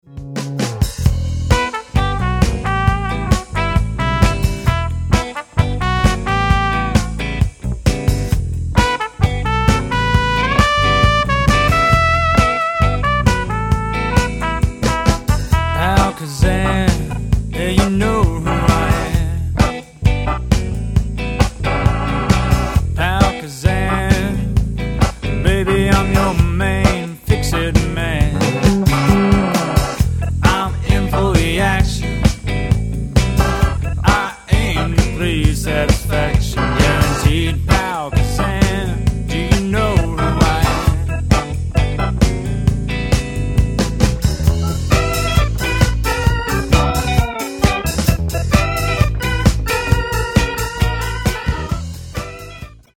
Recorded at Newmarket Studios